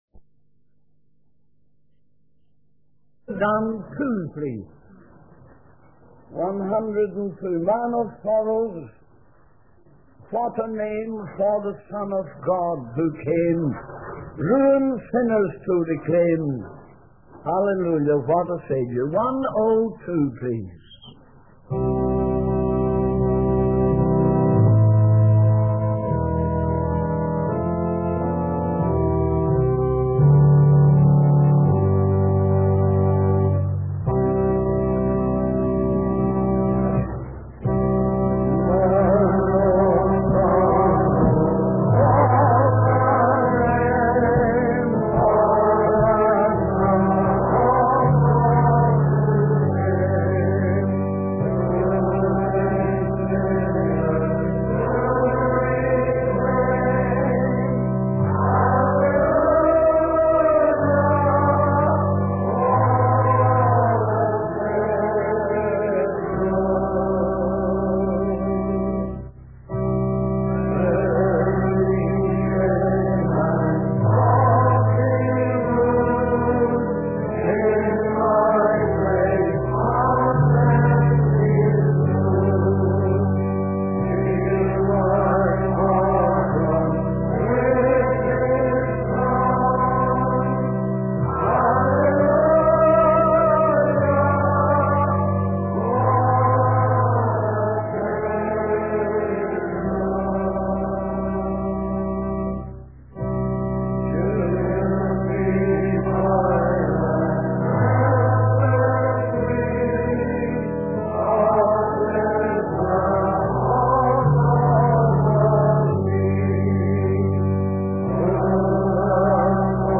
In this sermon, the preacher discusses the concept of Hallelujah and its significance in the Bible. He emphasizes that the word Hallelujah is mentioned four times in six short verses, indicating a call to praise God.